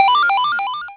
SFX
TetrisLine4.wav